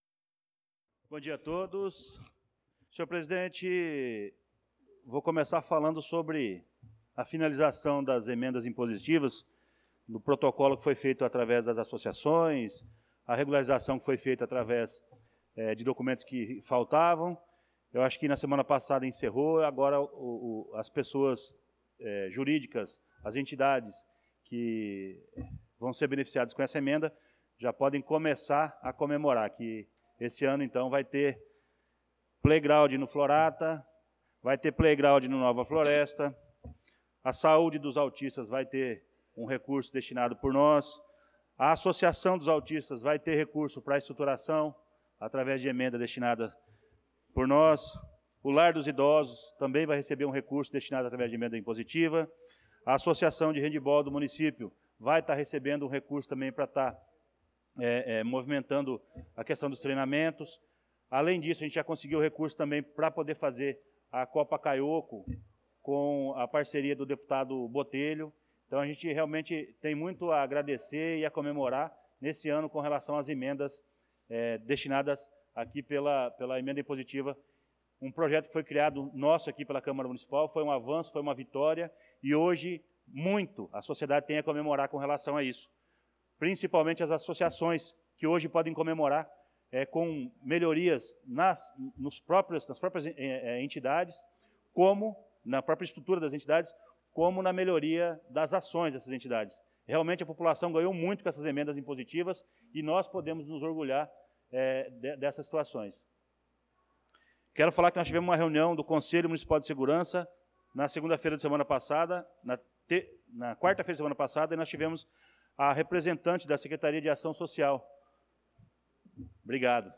Pronunciamento do vereador Luciano da Silva na Sessão Ordinária do dia 09/06/2025